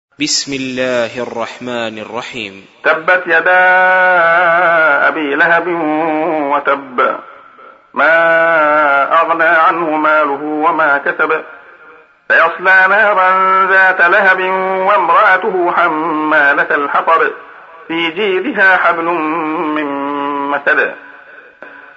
سُورَةُ المَسَدِ بصوت الشيخ عبدالله الخياط